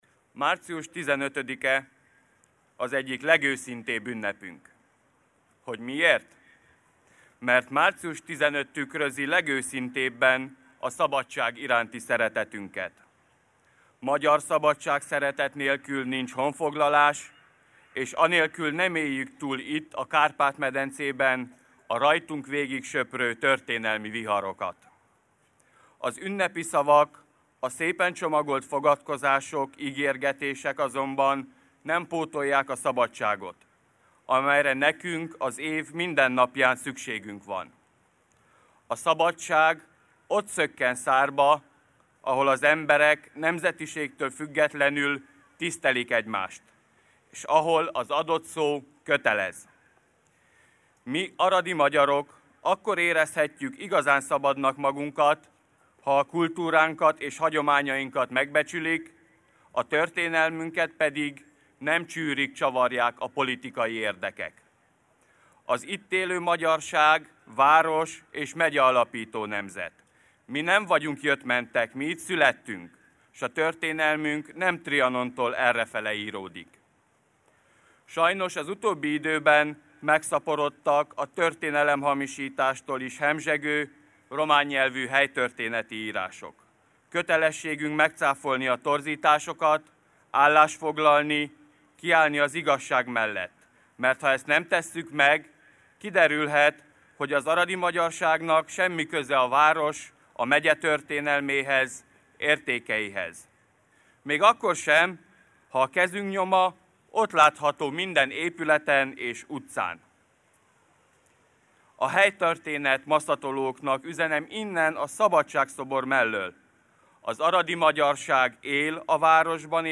Az aradi magyarság értékteremtő erejét, elvitathatatlan városalkotó szerepét hangsúlyozta március 15-i beszédében Faragó Péter, az RMDSZ Arad megyei szervezetének elnöke.
A beszédek sorát Faragó Péter zárta, aki egyebek mellett emlékeztette az eső ellenére szép számban összegyűlt megemlékezőket, hogy a romániai magyarság jogainak megnyirbálására irányuló hatalmi törekvések miatt a magyarok még mindig nem érezhetik teljesen szabadnak magukat.
Hallgassa meg Faragó Péter beszédét teljes egészében.